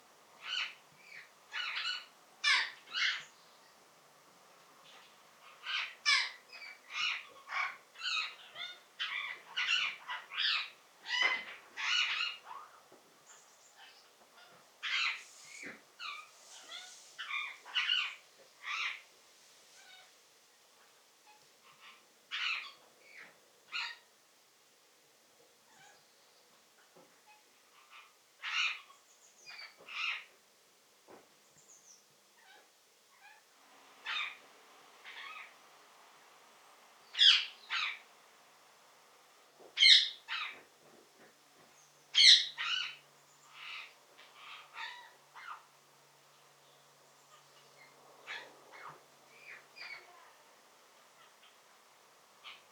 10. American Kestrel (Falco sparverius)
Sound: High-pitched “klee-klee-klee!”